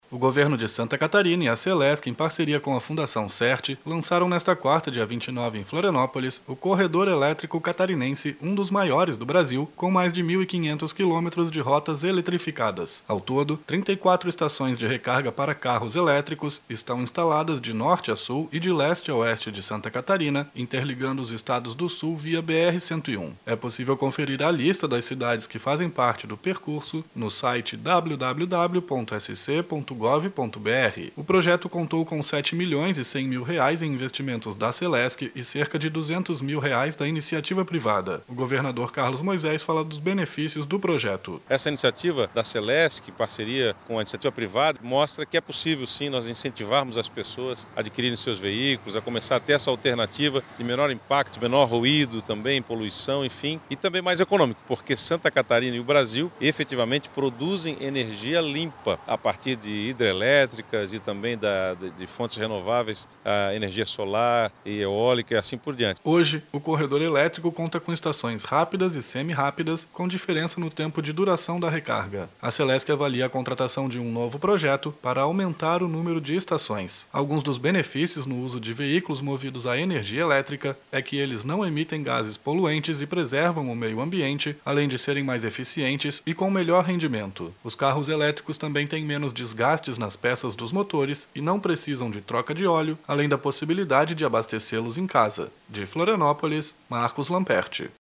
O governador Carlos Moisés fala dos benefícios do projeto: